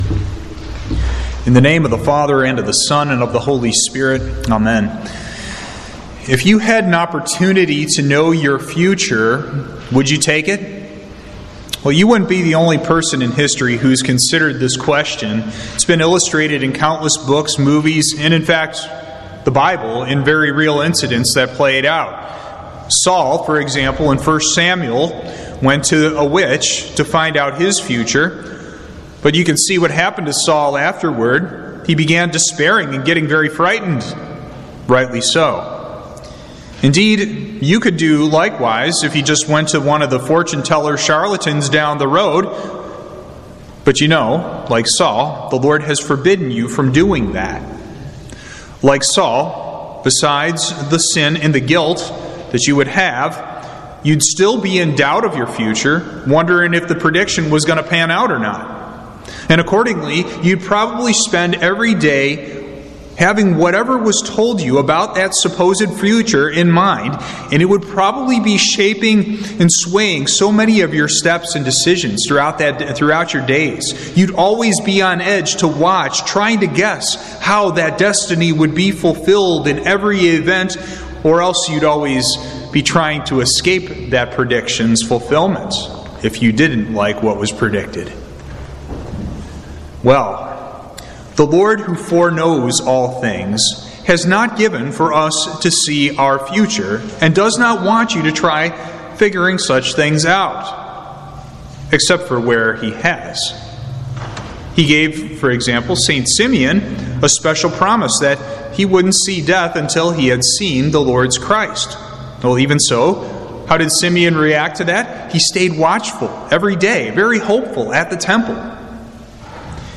Sermon
Evening Prayer – Advent 3